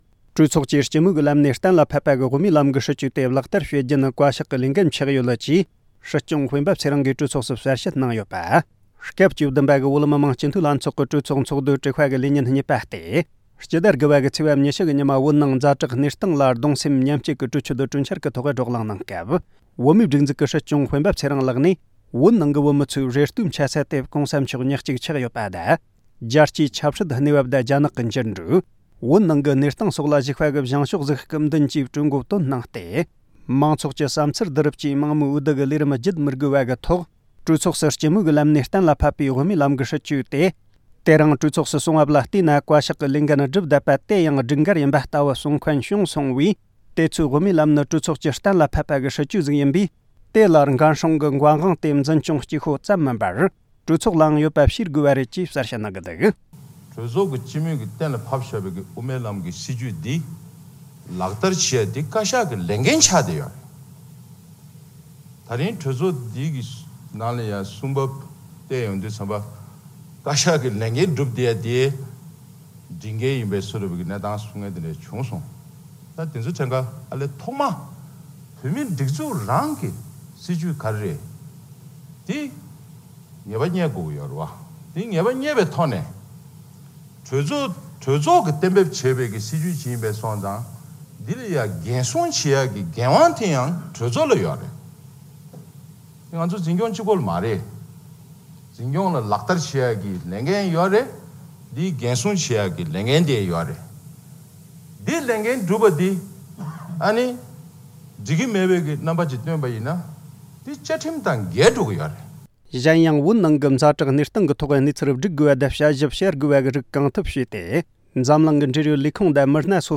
གྲོས་ཚོགས་ཀྱིས་སྤྱི་མོས་ཀྱི་ལམ་ནས་གཏན་ལ་ཕབ་པའི་དབུ་མའི་ལམ་གྱི་སྲིད་བྱུས་དེ་ལག་བསྟར་བྱེད་རྒྱུ་ནི་བཀའ་ཤག་གི་ལས་འགན་ཆགས་ཡོད་ལ་གྲོས་ཚོགས་ལའང་འགན་སྲུང་འགན་དབང་ཡོད་པ་ངེས་དགོས་ཅེས་སྲིད་སྐྱོང་སྤེན་པ་ཚེ་རིང་ལགས་ཀྱིས་གྲོས་ཚོགས་སུ་གསལ་བཤད་གནང་འདུག